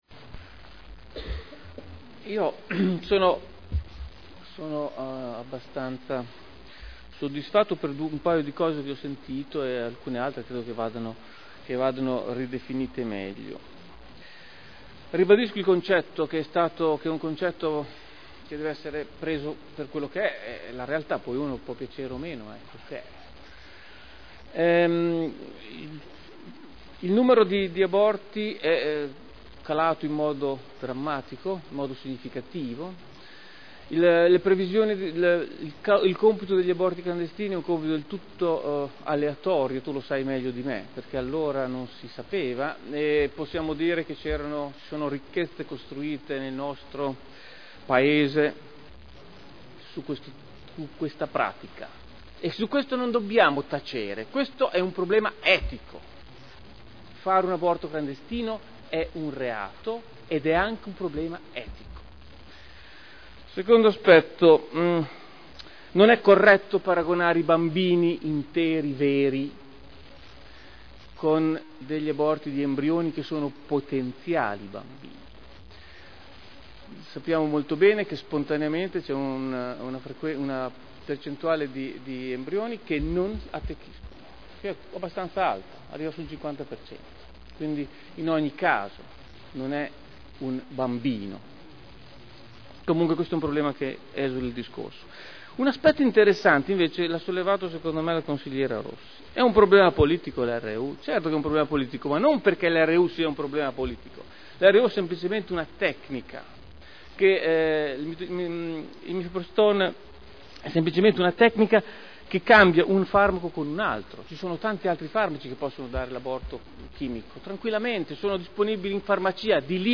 Seduta del 01/02/2010.